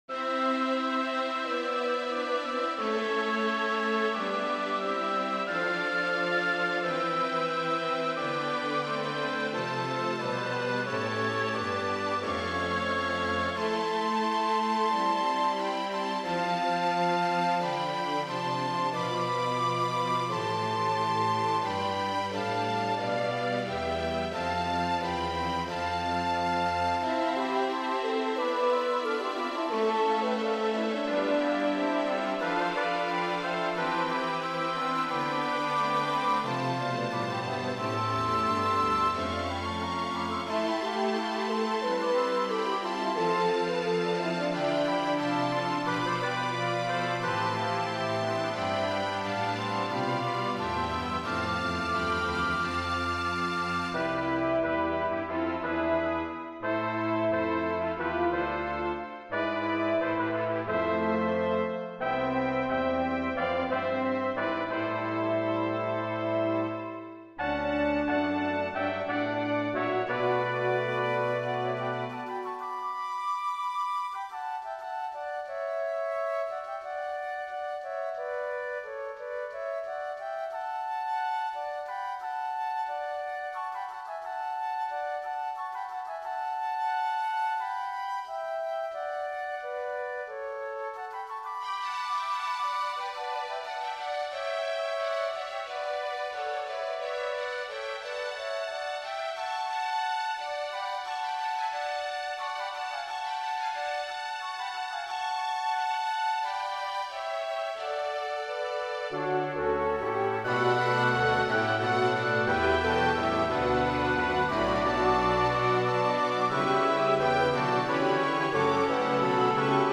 Roy Howard has composed a new work for orchestra that matches the theme of a 2008 GPAC gallery exhibit.
Flute, Soprano Recorder, Alto Recorder, Bb Clarinet, Oboe, Bassoon
Trumpets 1,2; French Horn, Trombone, Tuba
Violins 1,2; Viola, Cello, Bass